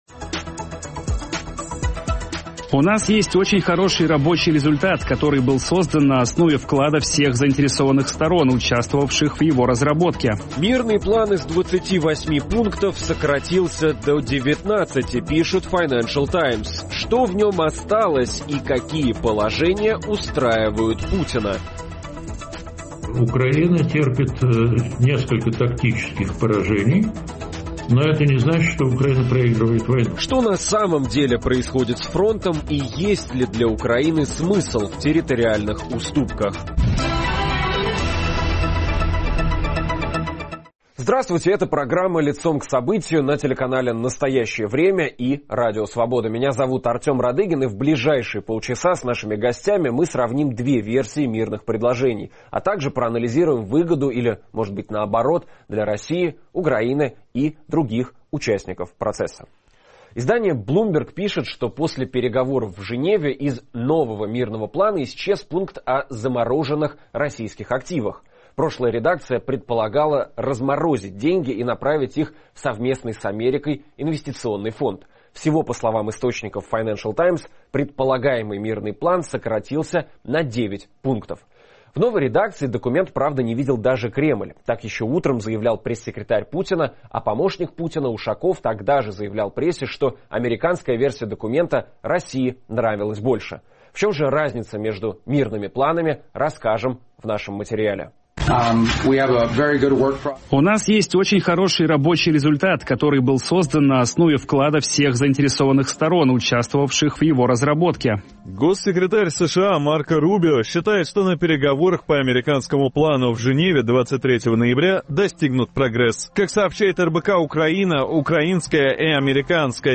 После переговоров в Женеве Украина и США подготовили доработанный документ по заключению мира. Обсуждаем новый мирный план и ситуацию на поле боя с политологами